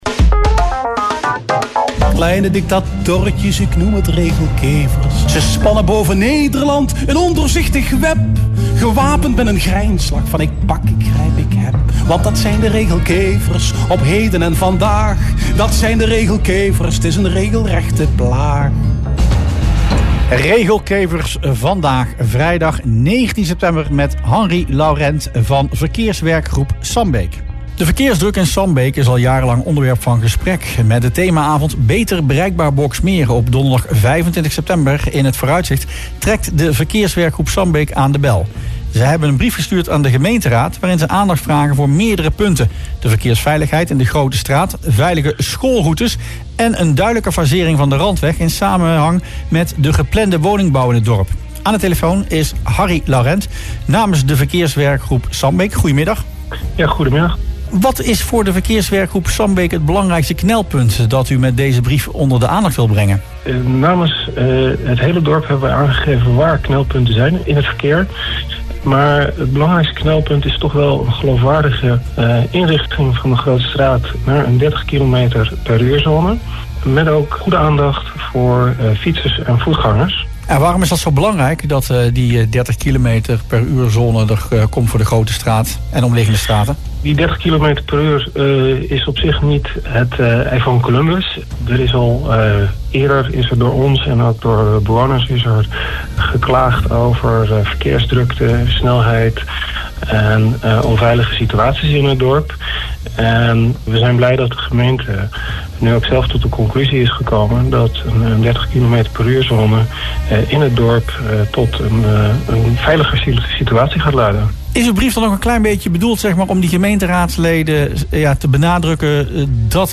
in het radioprogramma Rustplaats Lokkant